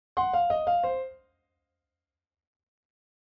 piano-blues-run-snippet2.mp3